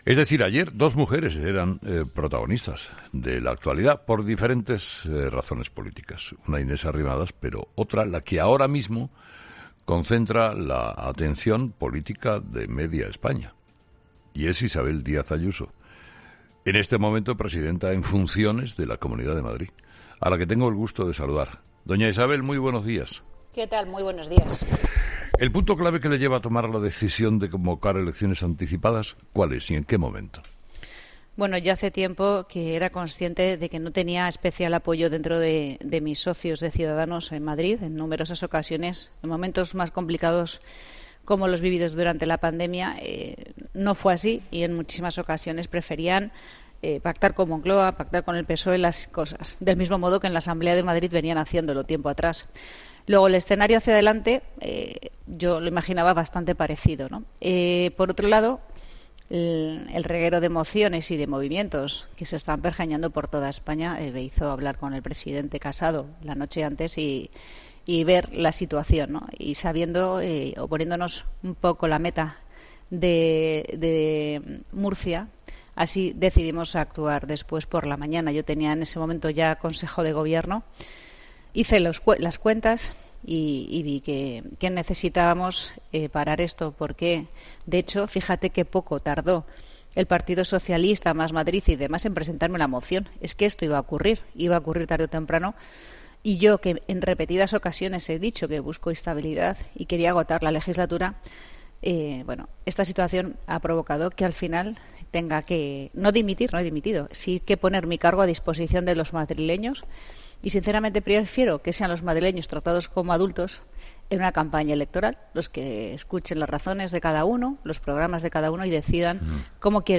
En una entrevista en "Herrera en COPE" horas después de anunciar la convocatoria de elecciones para el 4 de mayo ante el temor de una moción de censura aupada por la oposición, Ayuso ha manifestado que nunca se ha sentido respaldada por su socio de Gobierno.